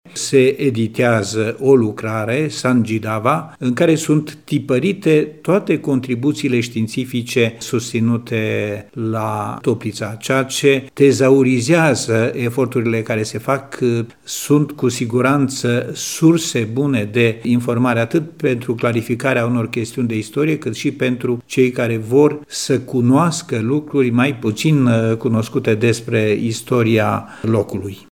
Evenimentul științific adună cu fiecare ediție noi documente și mărturii importante pentru generațiile viitoare, reunite în lucrarea SANGIDAVA care vede acum lumina tiparului, spune Nicolae Băciuț: